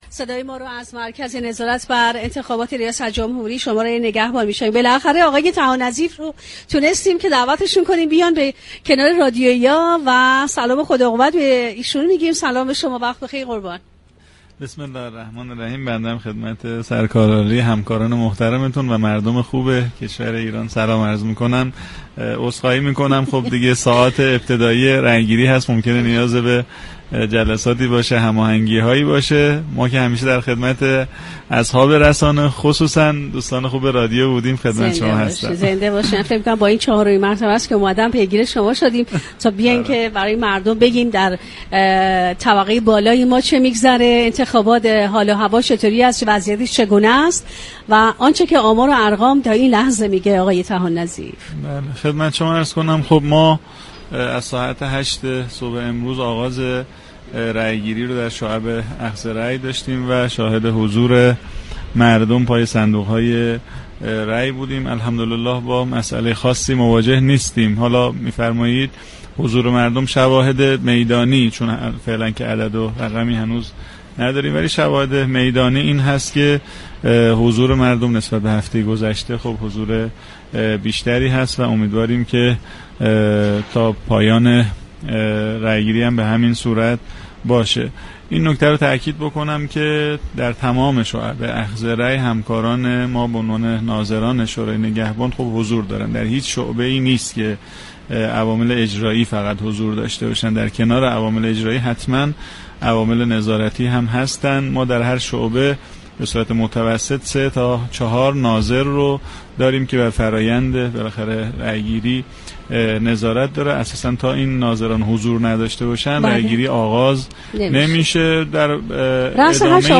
به گزارش پایگاه اطلاع رسانی رادیو تهران، هادی طحان‌نظیف سخنگوی شورای نگهبان در گفت و گو با ویژه برنامه انتخاباتی «شهر آرا» اظهار داشت: همكاران ما در ستاد مركزی وزارت كشور از طریق سامانه «مردم‌ناظر» با همه مردم ایران در ارتباط هستند.